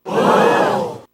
Play SSBB Crowd Gasb - SoundBoardGuy
Play, download and share SSBB Crowd Gasb original sound button!!!!
ssbb-crowd-gasb.mp3